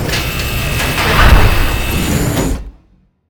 closeairlock-1.ogg